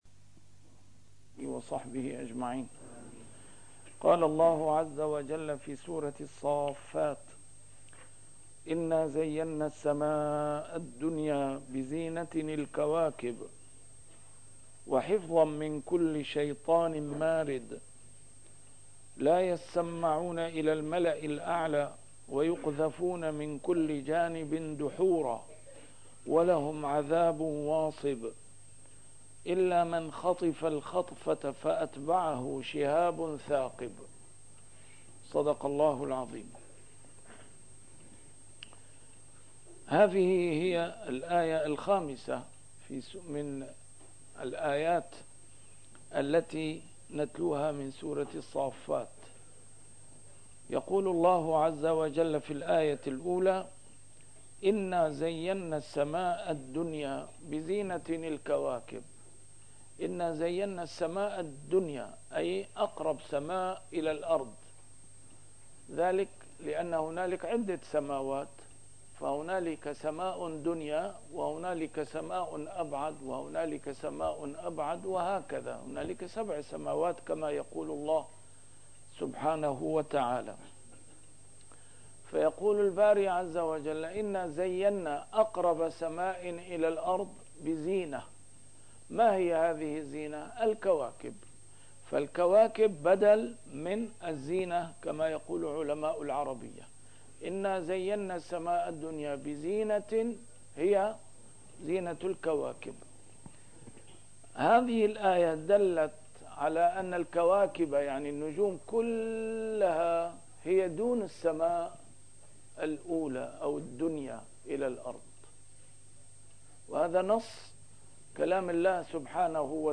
A MARTYR SCHOLAR: IMAM MUHAMMAD SAEED RAMADAN AL-BOUTI - الدروس العلمية - تفسير القرآن الكريم - تسجيل قديم - الدرس 445: الصافات 006-010